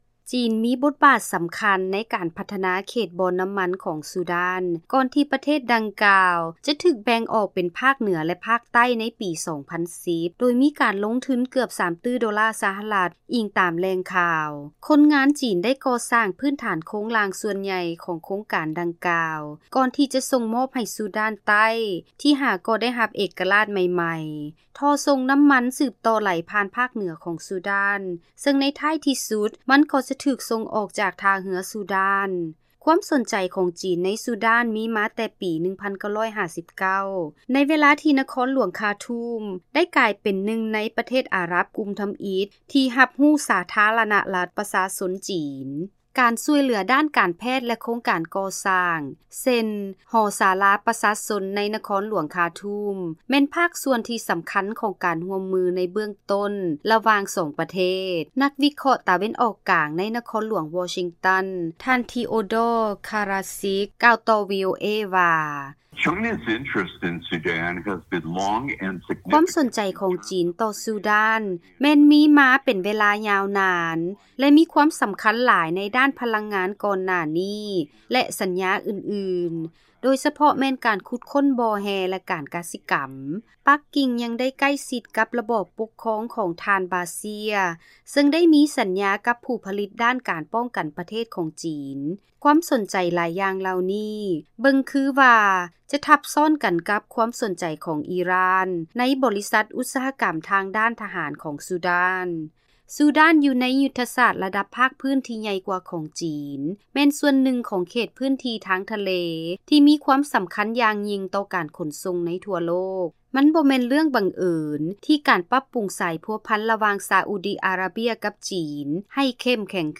ເຊີນຮັບຟັງລາຍງານ ກ່ຽວກັບ ຈີນສະແຫວງຫາການພົວພັນທີ່ເຂັ້ມແຂງຂຶ້ນ ກັບຊູດານ ທ່າມກາງ ການແກ່ງແຍ້ງແຂງຂັນໃນພາກພຶ້ນ ແລະ ສາກົນ